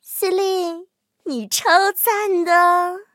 M10狼獾强化语音.OGG